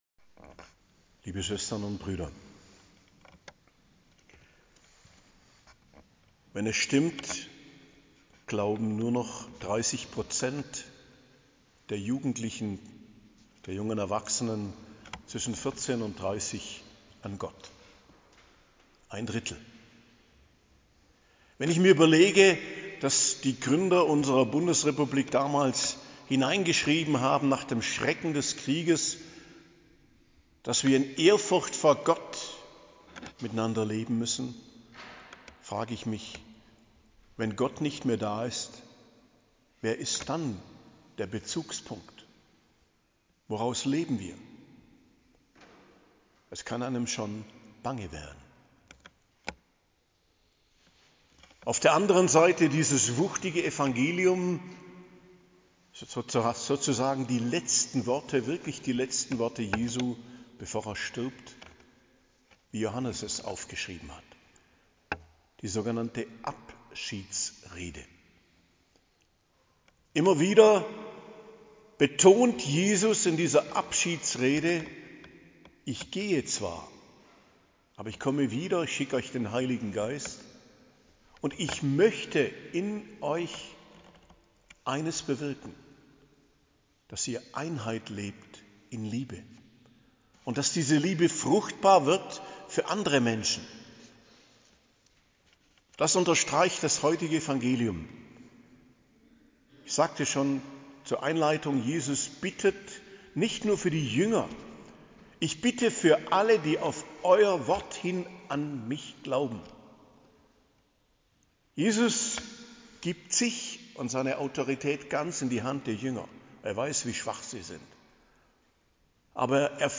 Predigt zum 7. Sonntag der Osterzeit, 1.06.2025 ~ Geistliches Zentrum Kloster Heiligkreuztal Podcast